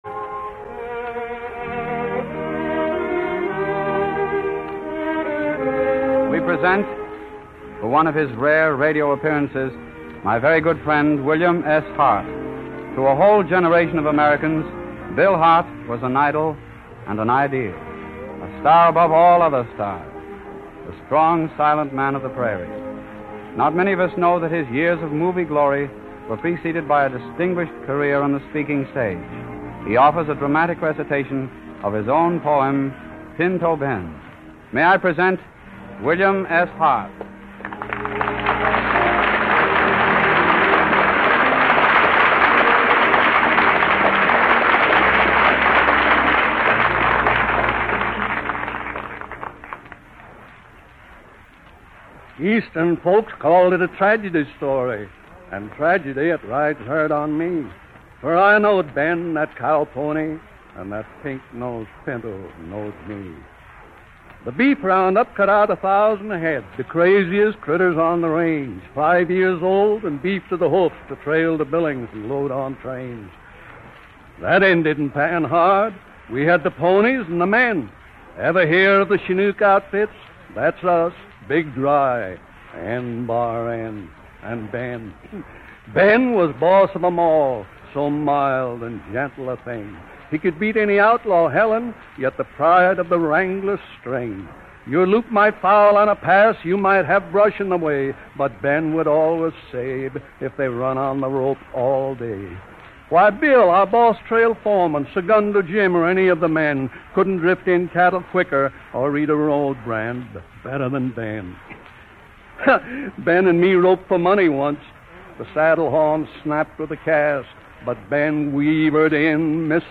But Hart occasionally made an appearance on radio in the 1930s to recite his own poem, “Pinto Ben.” Here is Rudy Vallee introducing him on the December 13, 1934 broadcast of the Fleischmann’s Hour: